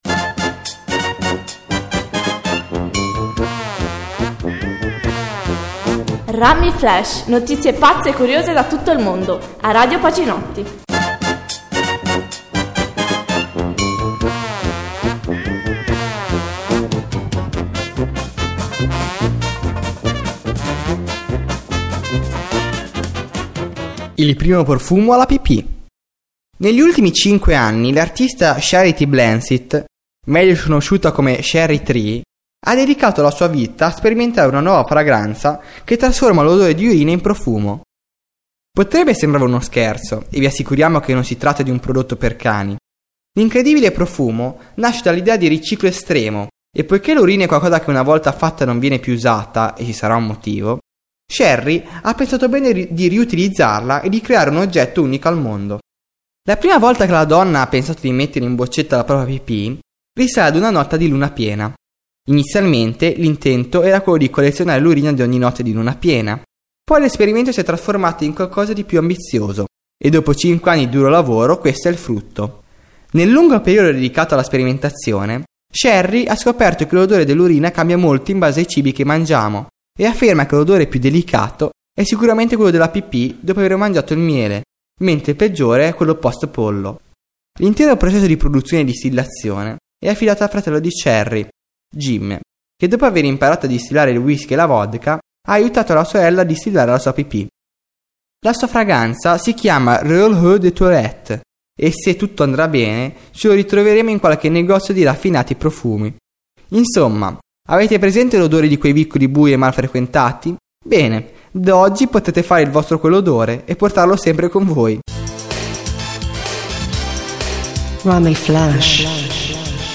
Radioweb Pacinotti Notizie curiose e brano di musica con relazione alla notizia